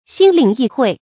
心领意会 xīn lǐng yì huì
心领意会发音